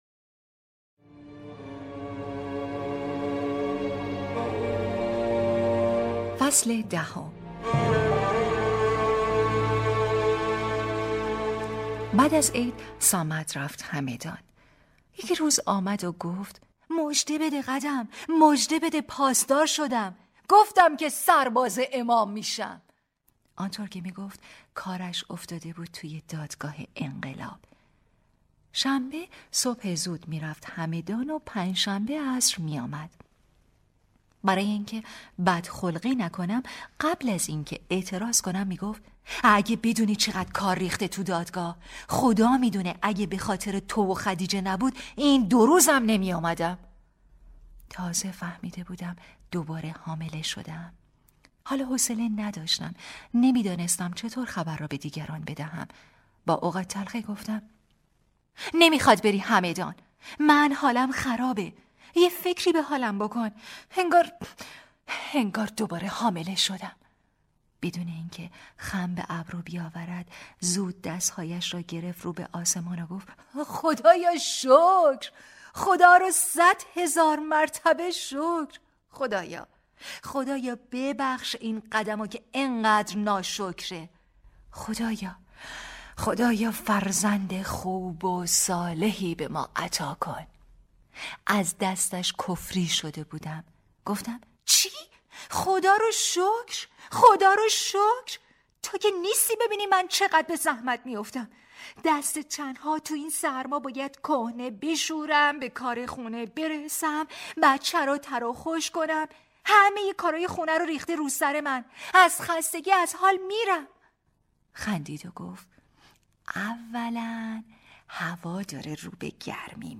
کتاب صوتی | دختر شینا (08)
کتاب صوتی دختر شیناخاطرات همسر سردار شهید حاج ستار ابراهیمی هژیرفصل دهم